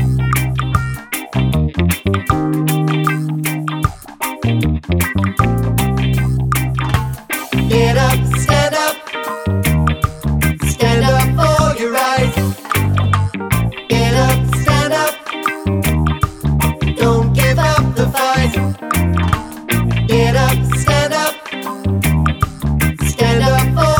no Backing Vocals Reggae 3:16 Buy £1.50